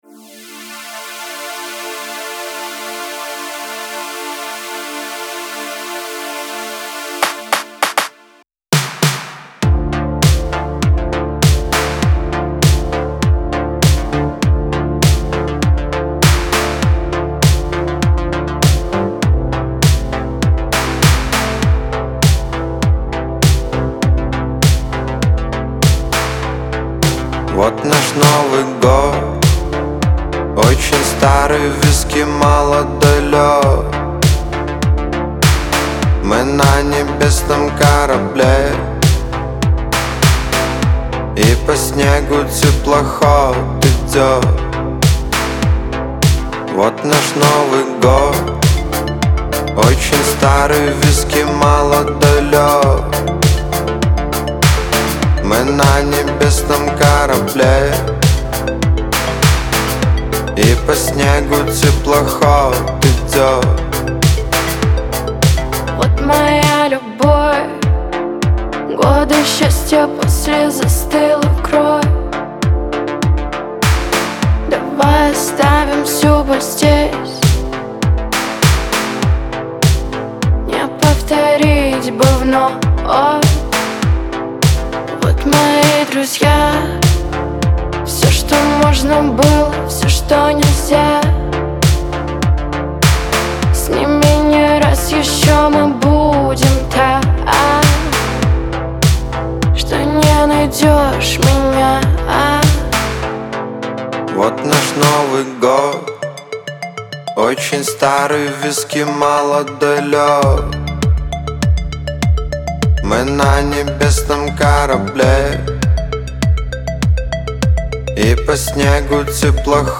энергичная и веселая песня